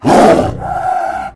CosmicRageSounds / ogg / general / combat / creatures / tiger / he / taunt1.ogg